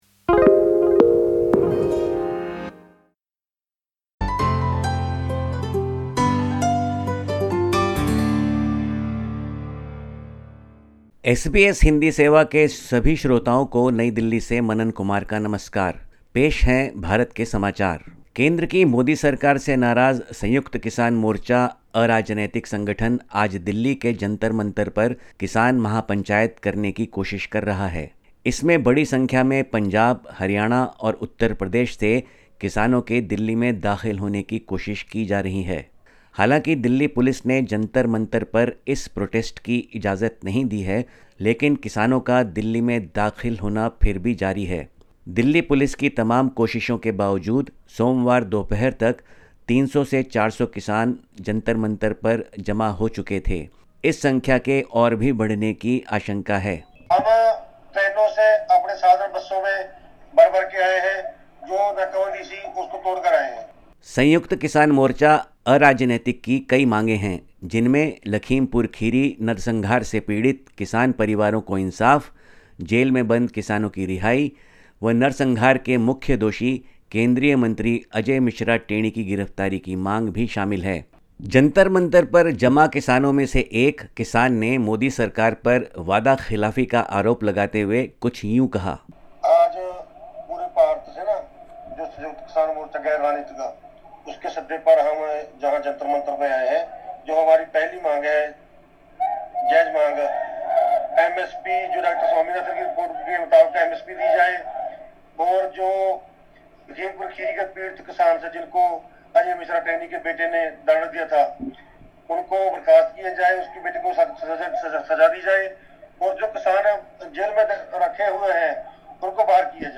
Listen to the latest SBS Hindi news from India. 22/08/2022